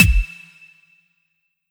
nightcore-kick.wav